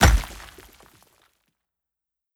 Hit_Concrete 02.wav